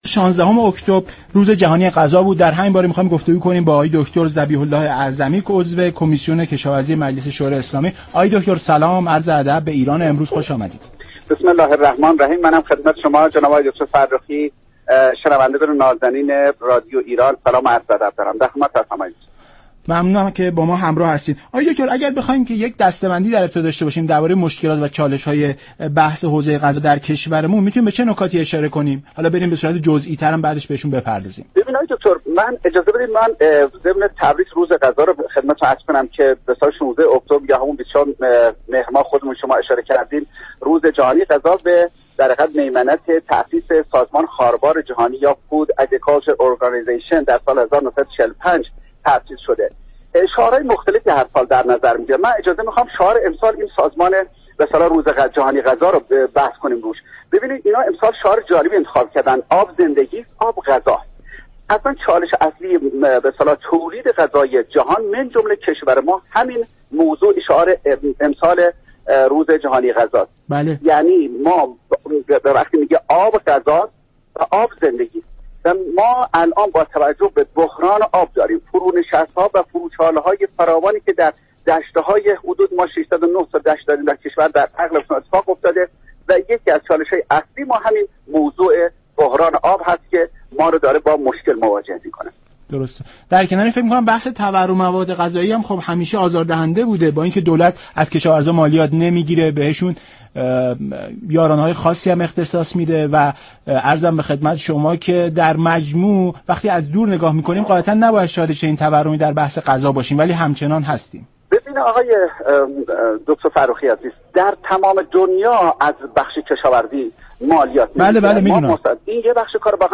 به گزارش شبكه رادیویی ایران، ذبیح اله اعظمی عضو كمیسیون كشاورزی مجلس در برنامه ایران امروز به چالش های كشور در حوزه غذا پرداخت و گفت: امروز آب اصلی ترین چالش ایران و جهان در تولید غذا است، در عصری كه جهان با فرونشست ها و فرو چاله ها روبروست بحران آب به شدت جهان مان و به تبع آن ایران را با مشكل مواجه كرده است.